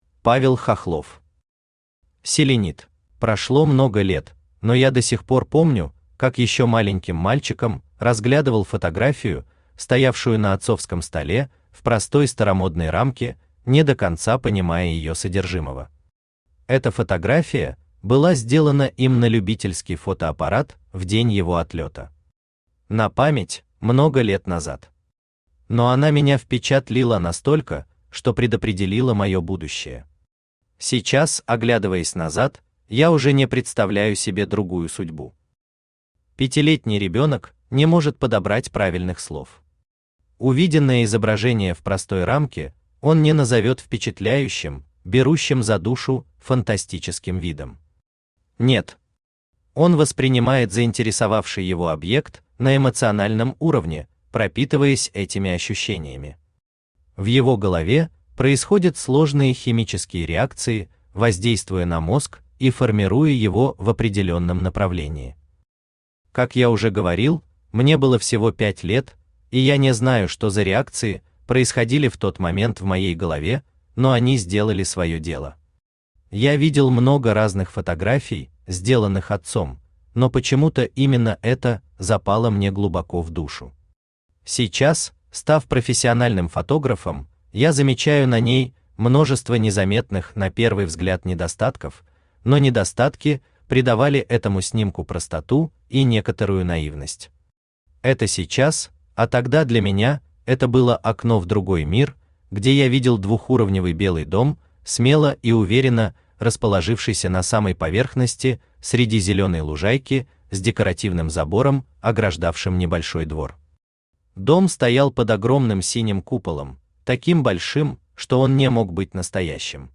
Aудиокнига Селенит Автор Павел Хохлов Читает аудиокнигу Авточтец ЛитРес.